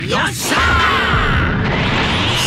Vegito's YOSHA from Dragon Ball Fighterz